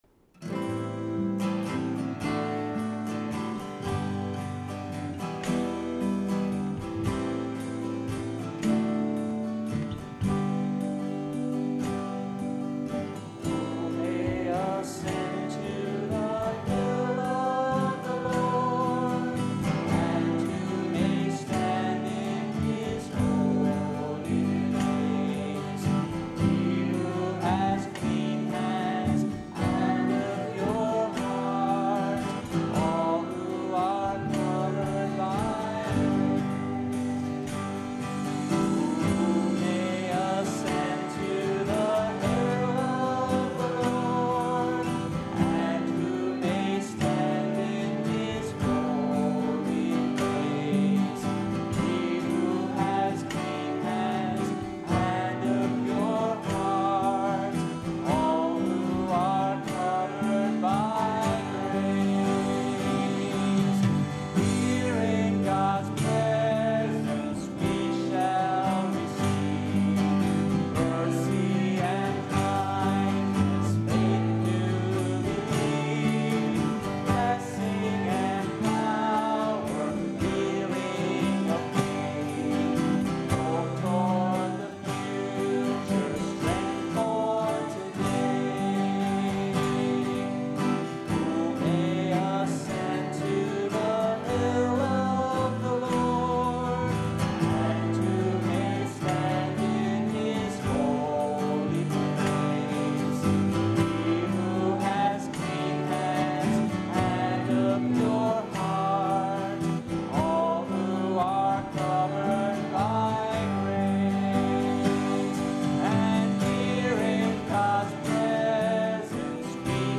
Based on Psalm 24:3,  the ascending and descending melodies